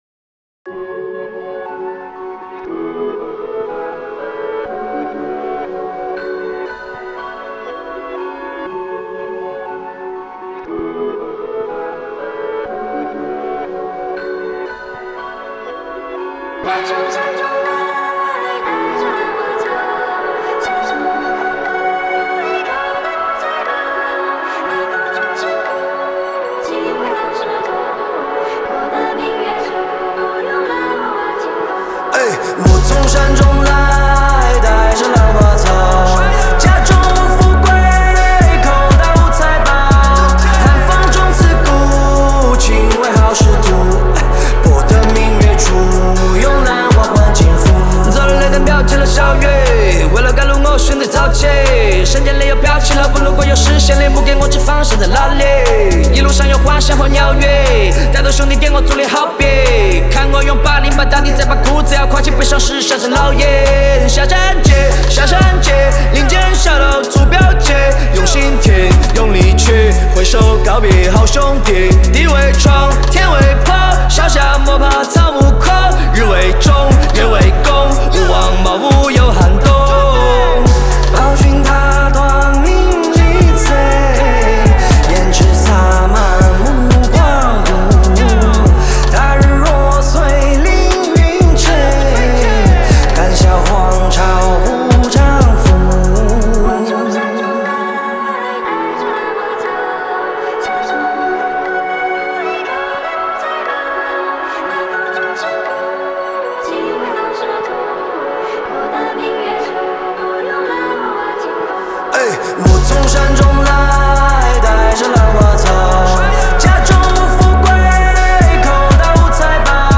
Ps：在线试听为压缩音质节选，体验无损音质请下载完整版
和声